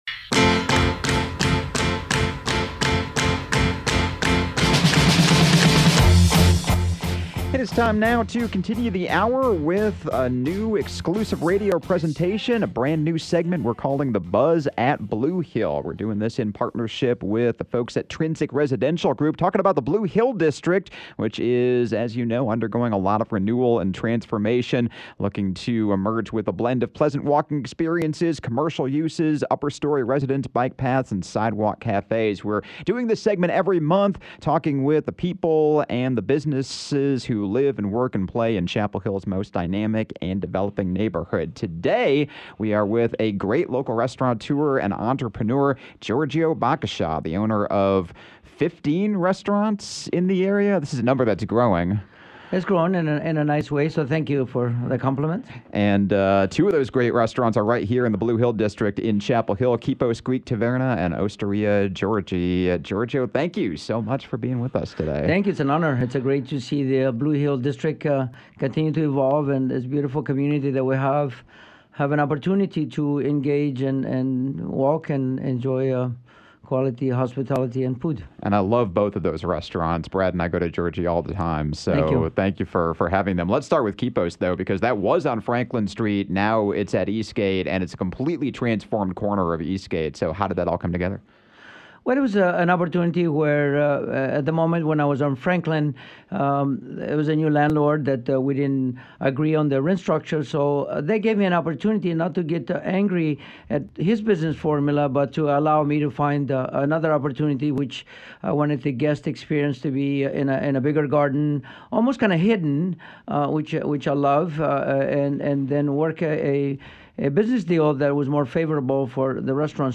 “The Buzz at Blue Hill” is an exclusive radio presentation in partnership with Trinsic Residential Group on 97.9 The Hill. Each month, a new guest will be sharing their unique perspectives and stories as people who live, work and play as part of Chapel Hill’s most dynamic and developing neighborhood.